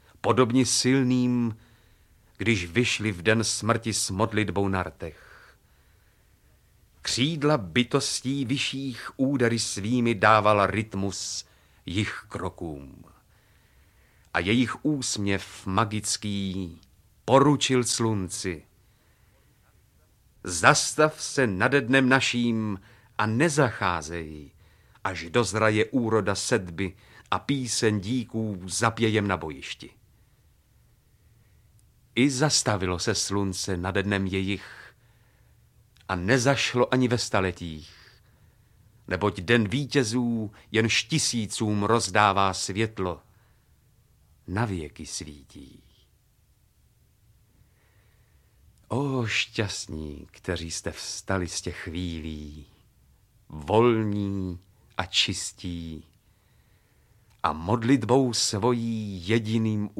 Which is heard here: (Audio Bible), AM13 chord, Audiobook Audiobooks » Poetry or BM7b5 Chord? Audiobook Audiobooks » Poetry